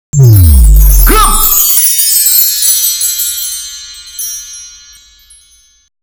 Box_small.wav